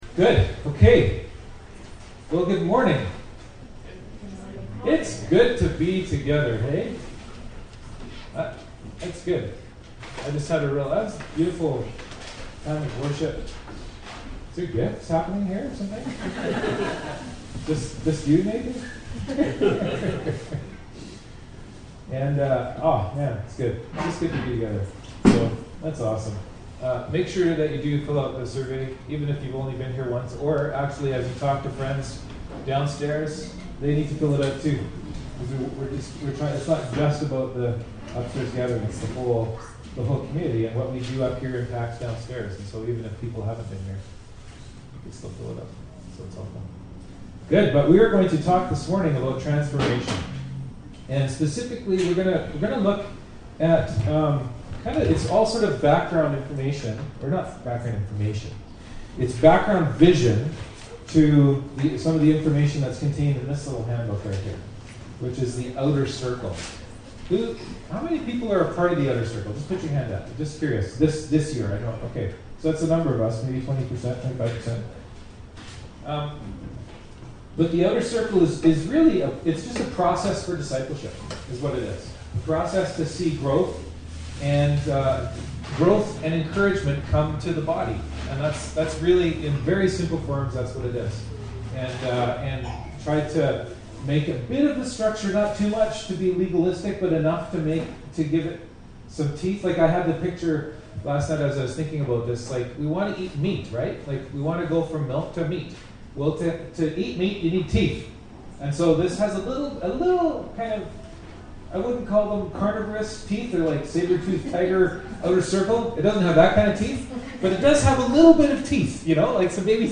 Service Type: Upstairs Gathering